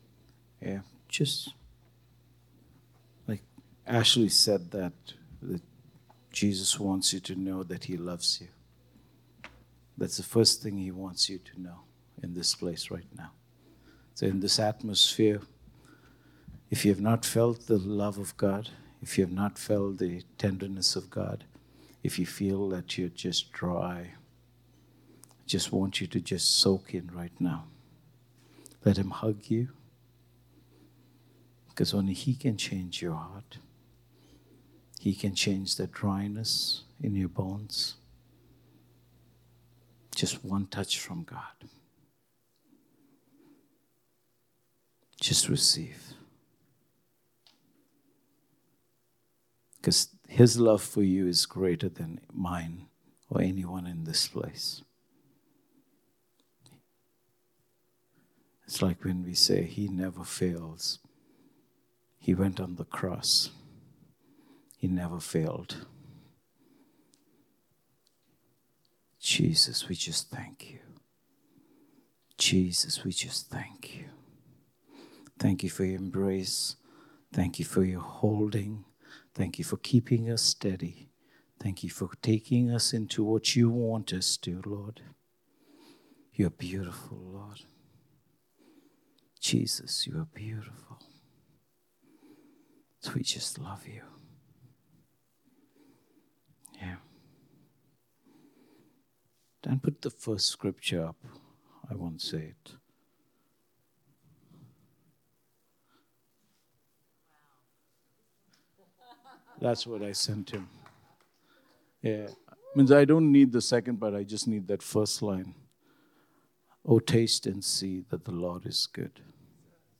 Come Taste and See – SERMONS